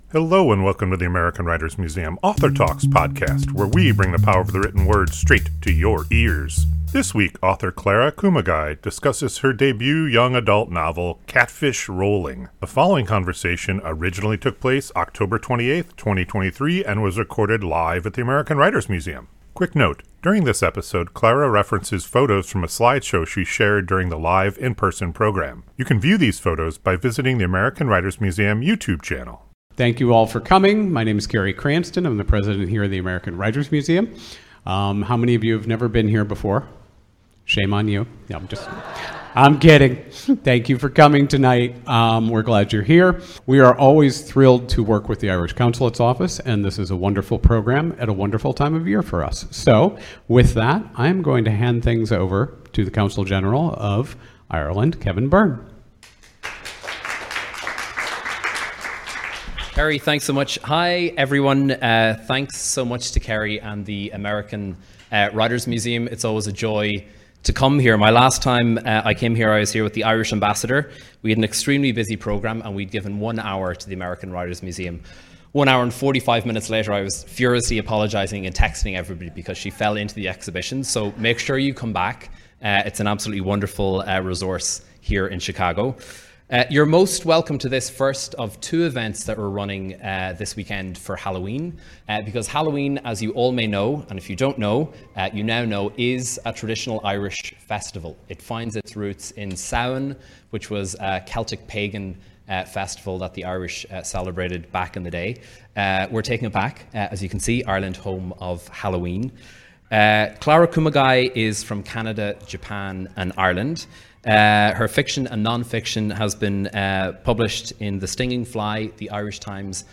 This conversation originally took place October 28, 2023 and was recorded live at the American Writers Museum.